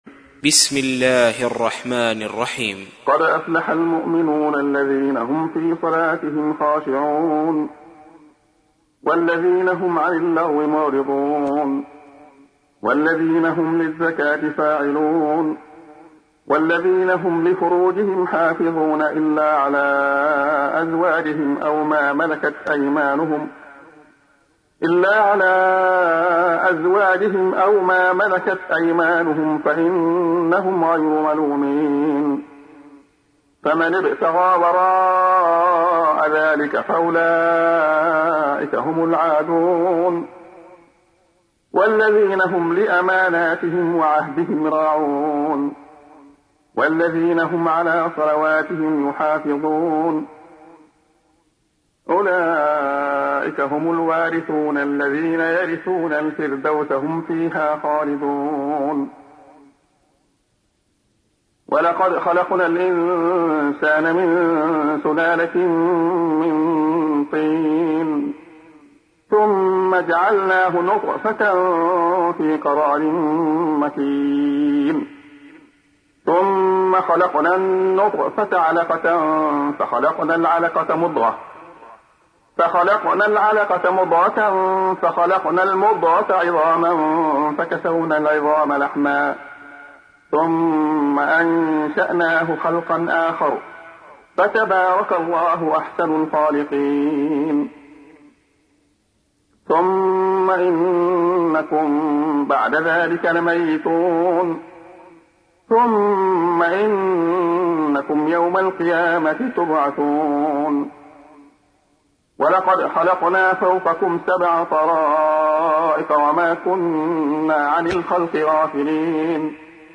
تحميل : 23. سورة المؤمنون / القارئ عبد الله خياط / القرآن الكريم / موقع يا حسين